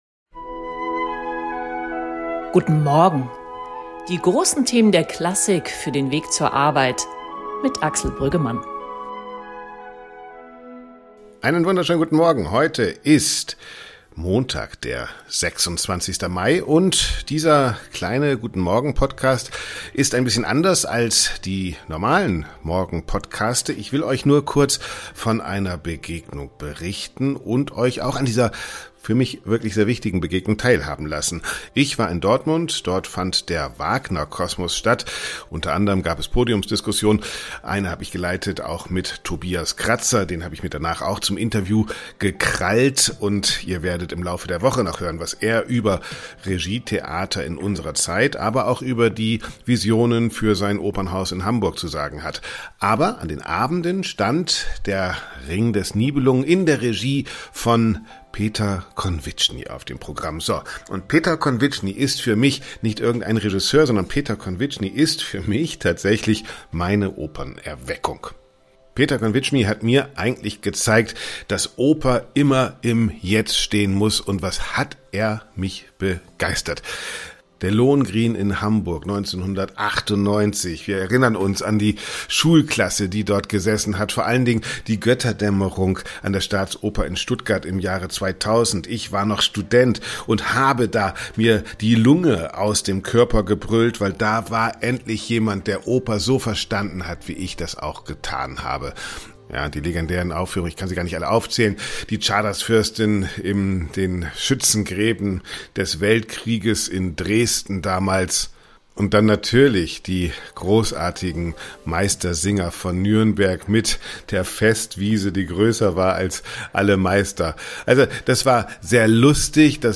Opernregisseur Peter Konwitschny, der kürzlich seinen 80. Geburtstag feierte, hat sich am Rande des Wagner Kosmos in Dortmund mit Axel Brüggemann über den Wandel der Systeme, die Rolle der Regiearbeit und die existenzielle Bedeutung seiner Kunst ausgetauscht.